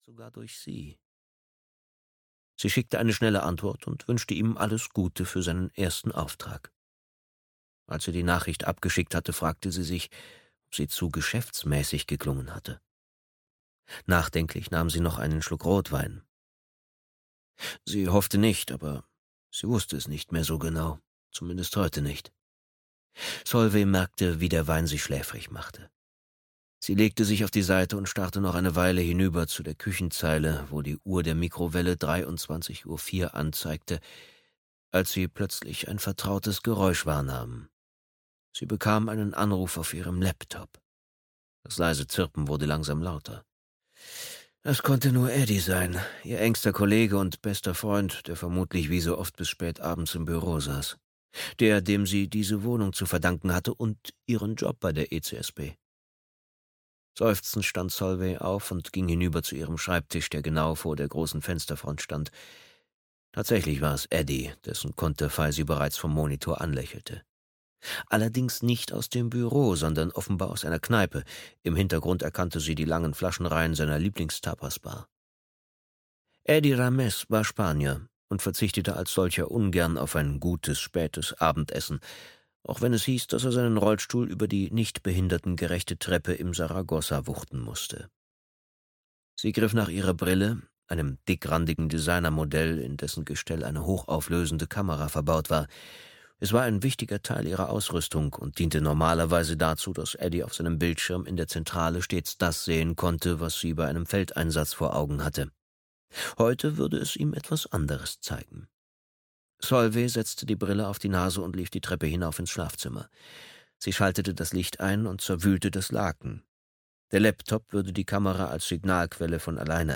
Biest (Solveigh Lang-Reihe 2) - Jenk Saborowski - Hörbuch